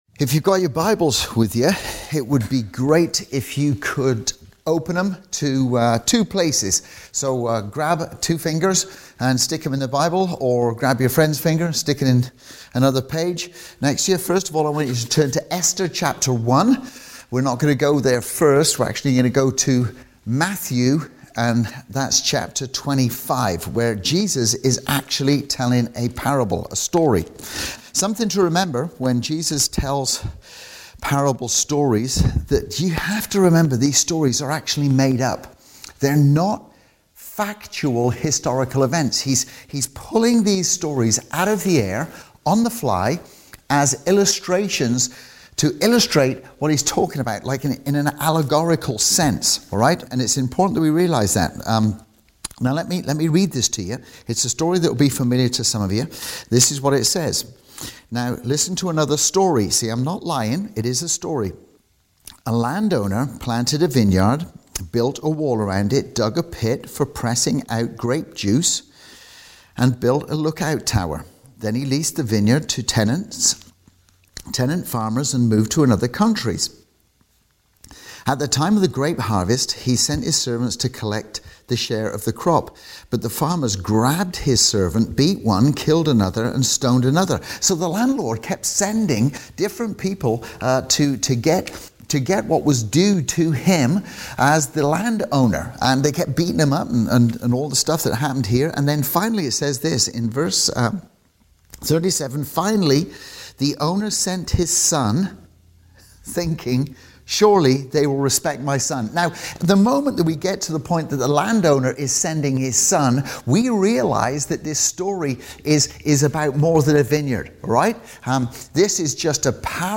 Our Sunday Message